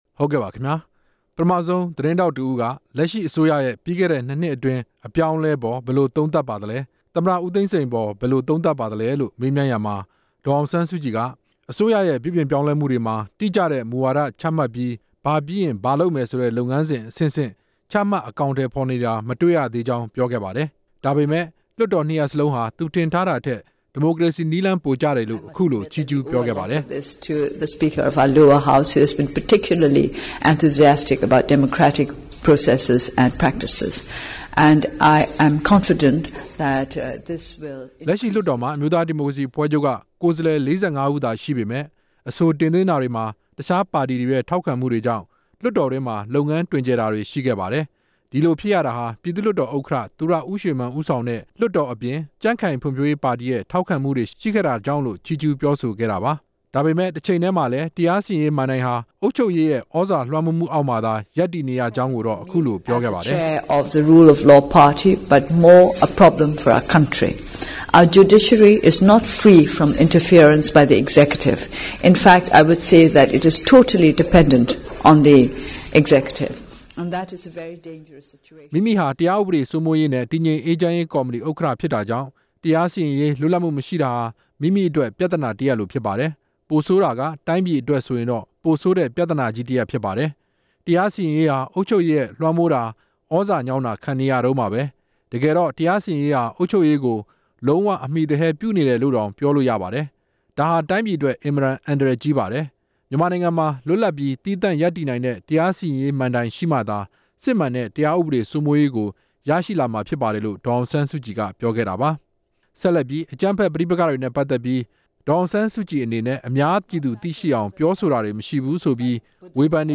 ဒေါ်အောင်ဆန်းစုကြည်ရဲ့ ဖြေကြားချက်
မနေ့က ဂျပန်နိုင်ငံ တိုကျိုမြို့ သတင်းထောက်များကလပ်မှာ ကျင်းပတဲ့ သတင်းစာရှင်းလင်းပွဲမှာ လွန်ခဲ့တဲ့ ၂ နှစ်အတွင်း ပြောင်းလဲမှုတွေ မြန်ဆန်လွန်းတာကို ဘယ်လိုသုံးသပ်ပါသလဲ၊ သမ္မတ ဦးသိန်းစိန်အပေါ် ဘယ်လိုမြင်ပါသလဲလို့ မေးမြန်းရာမှာ ဒေါ်အောင်ဆန်း စုကြည်က ပြန်လည်ဖြေကြားခဲ့တာပါ။